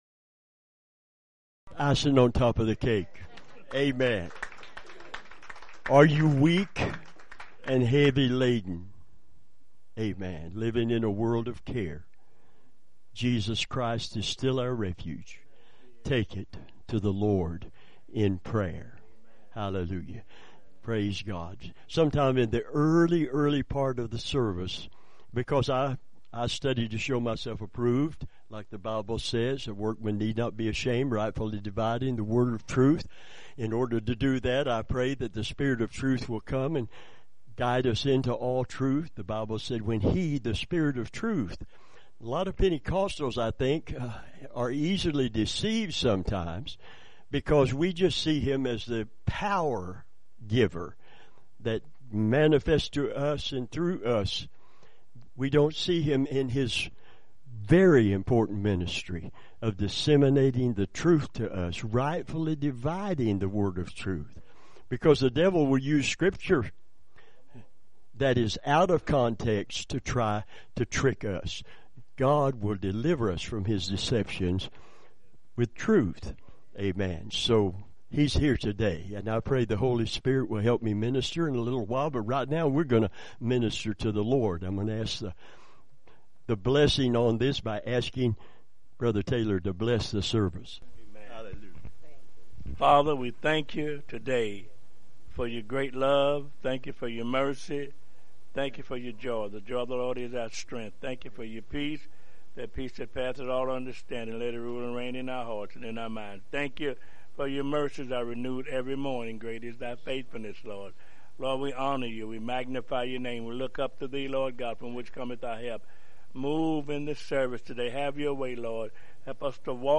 Revival Sermons , right place right time